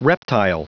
Prononciation du mot reptile en anglais (fichier audio)
Prononciation du mot : reptile